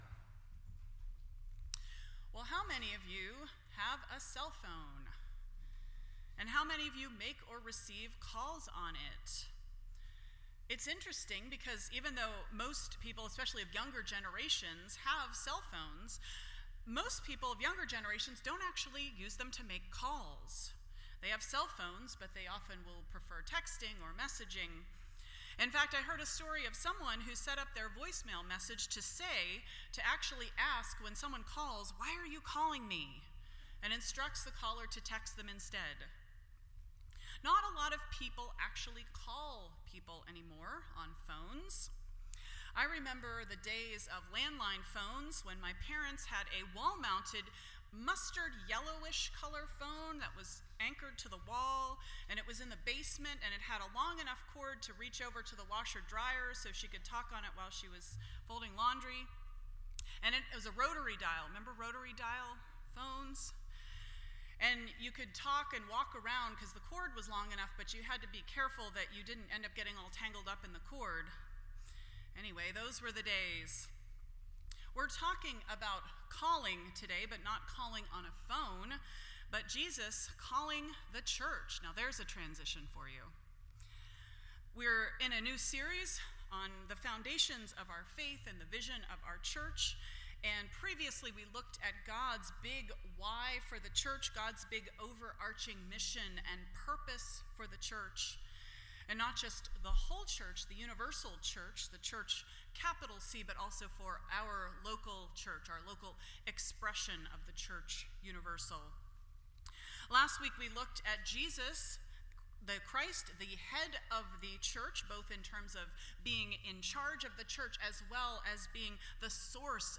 Foundations Service Type: Sunday Morning %todo_render% Share This Story